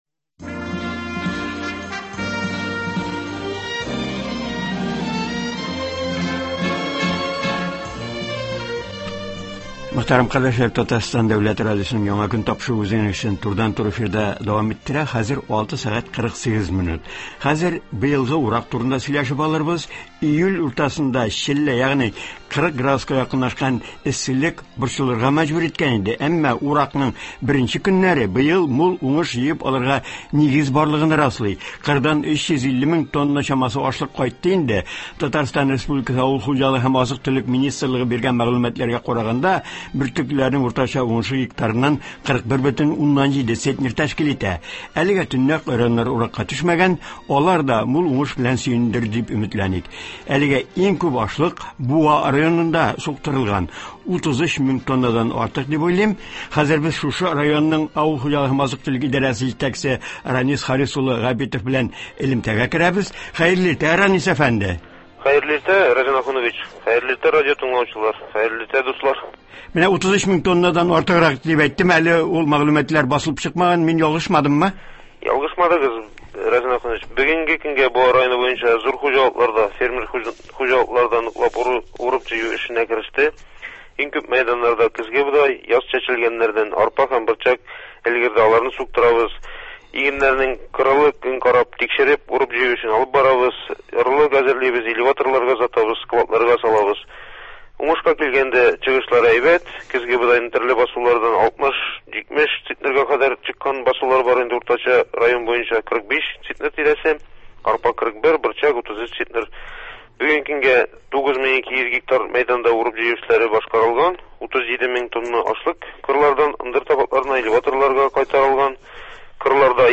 Болар хакында телефон элемтәсендә